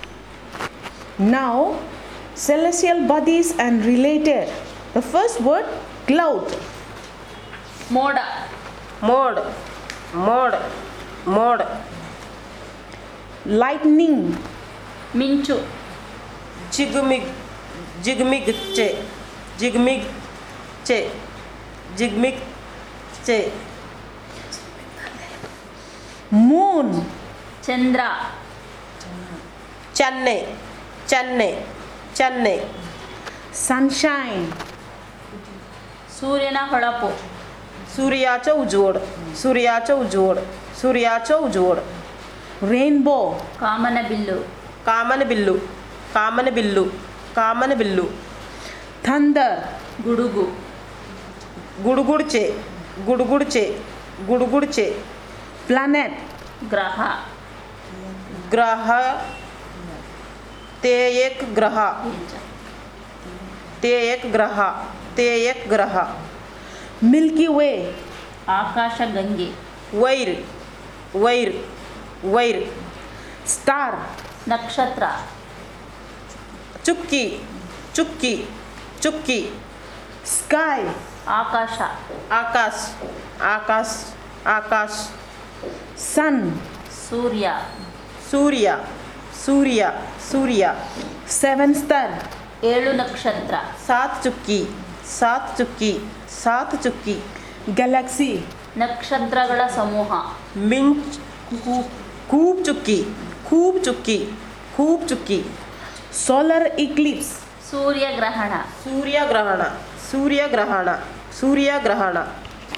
Elicitation of words about celestial bodies and related